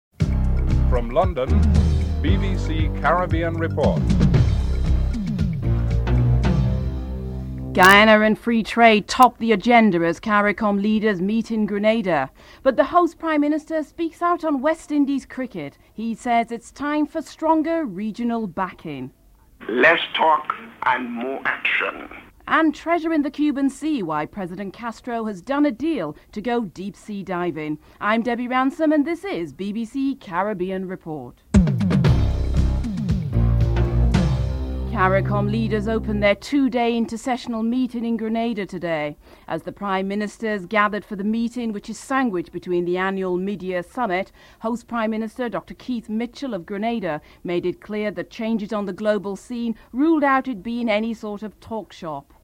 7. Recap of top stories (14:51-15:14)